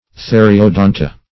Theriodonta \The`ri*o*don"ta\